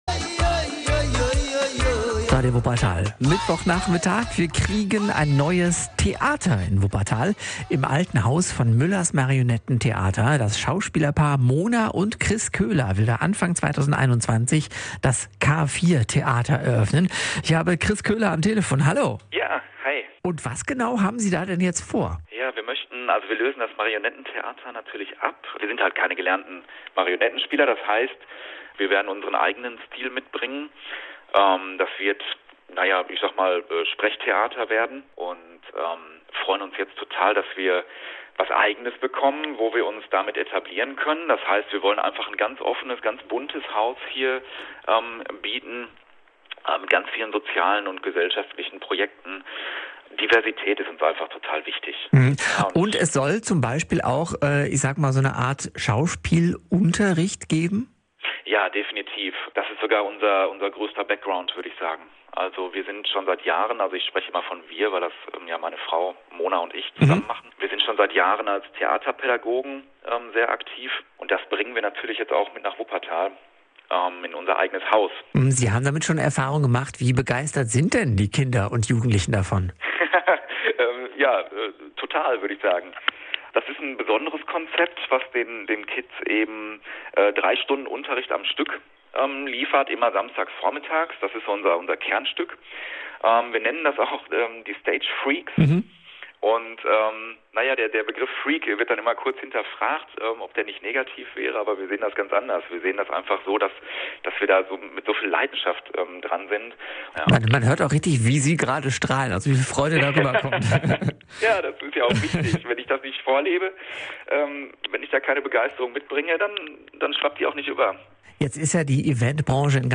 Unsere persönliche Vorstellung
Mitschnitt-K4-Theater-Radio-Wuppertal.mp3